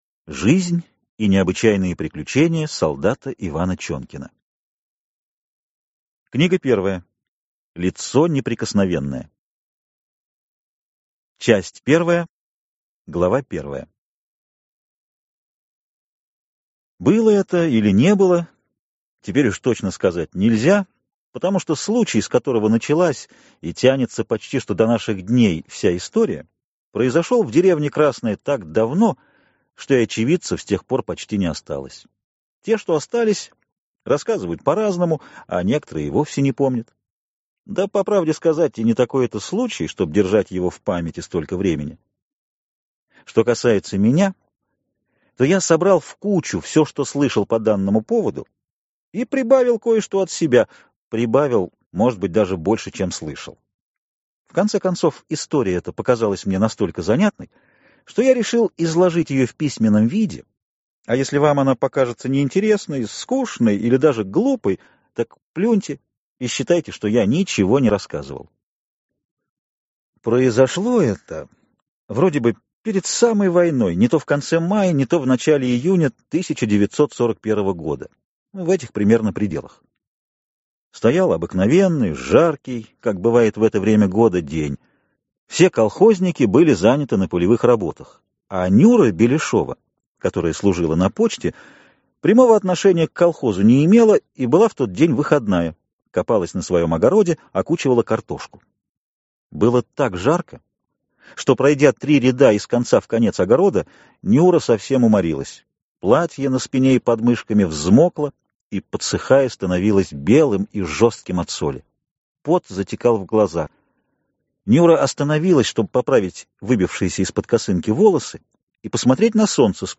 Аудиокнига Жизнь и необычные приключения солдата Ивана Чонкина | Библиотека аудиокниг